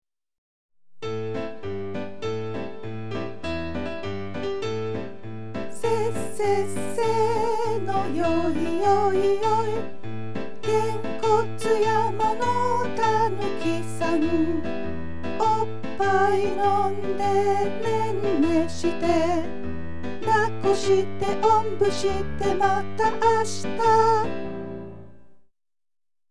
Song /